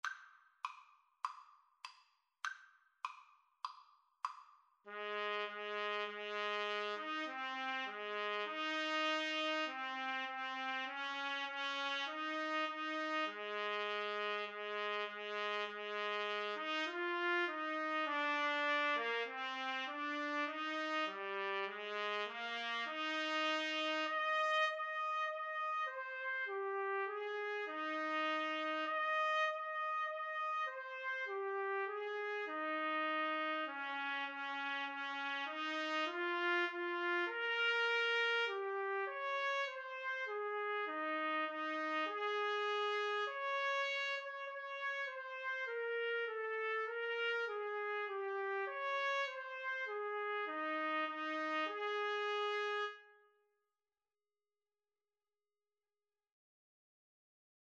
4/4 (View more 4/4 Music)
Trumpet Duet  (View more Easy Trumpet Duet Music)